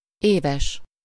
Ääntäminen
Ääntäminen France: IPA: [a.nɥɛl] Haettu sana löytyi näillä lähdekielillä: ranska Käännös Ääninäyte Adjektiivit 1. éves Muut/tuntemattomat 2. évenkénti 3. évi Suku: m .